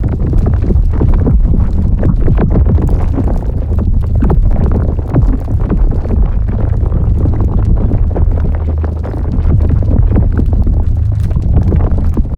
gravi_idle01.ogg